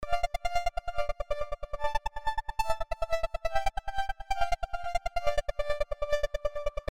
莫尔斯代码
Tag: 140 bpm Electronic Loops Synth Loops 1.15 MB wav Key : Unknown